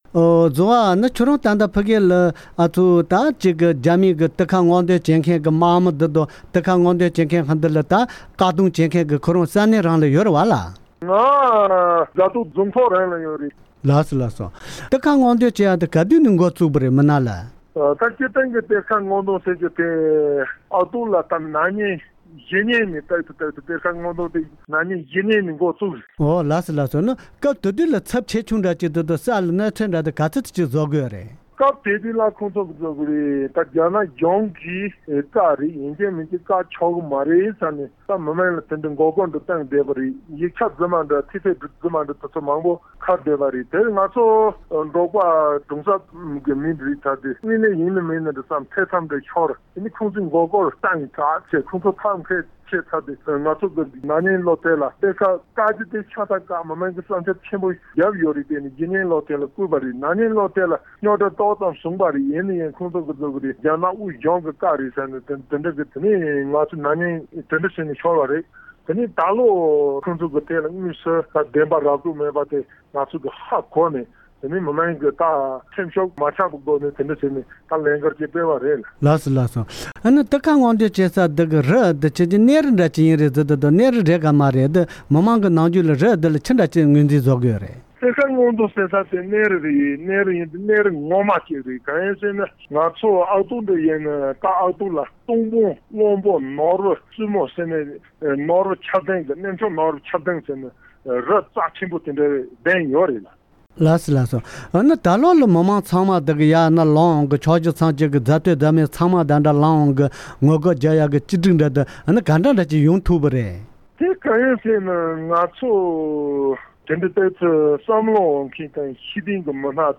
ས་གནས་སུ་འབྲེལ་ཡོད་མི་སྣར་བཅར་འདྲི་ཞུས་པར